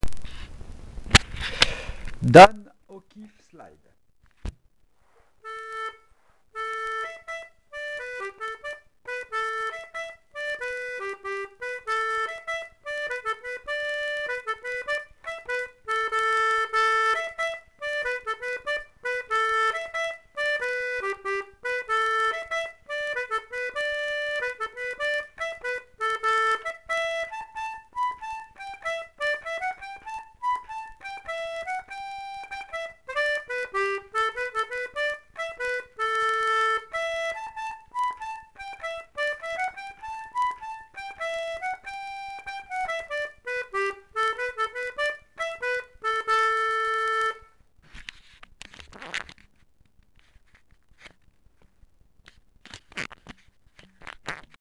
l'atelier d'accordéon diatonique